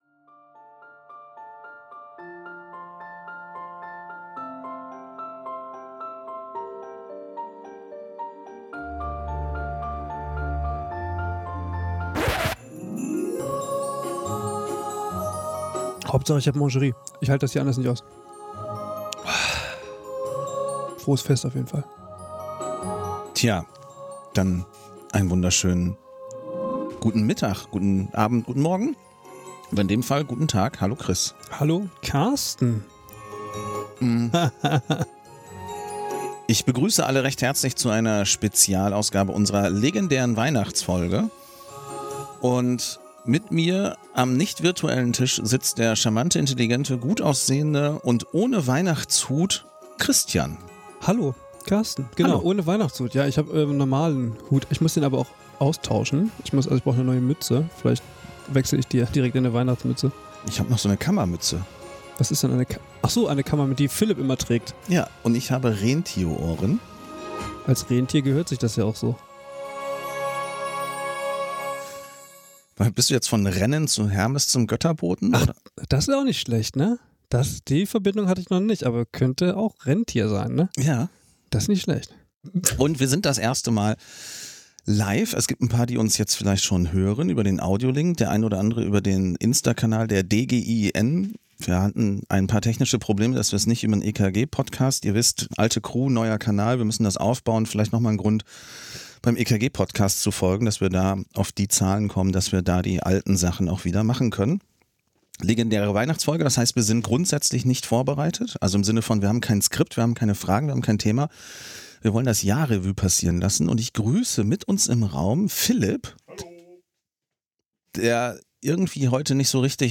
In einer improvisierten Weihnachts-Spezialfolge